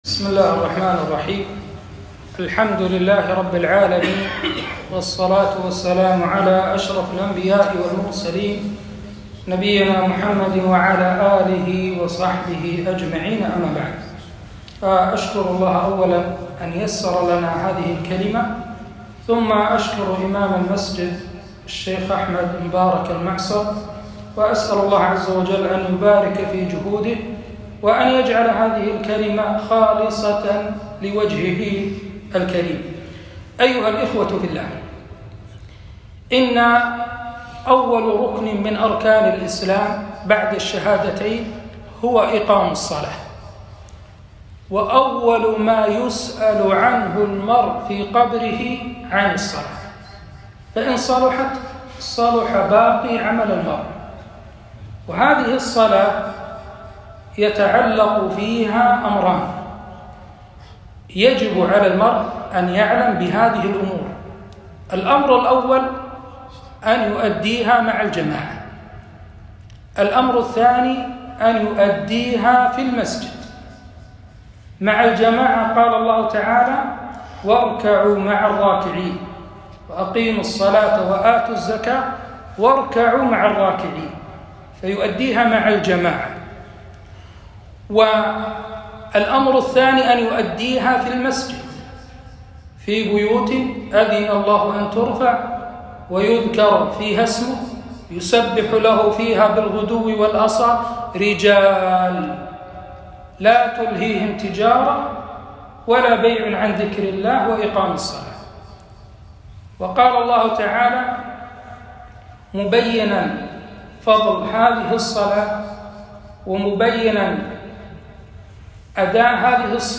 كلمة - وجوب صلاة الجماعة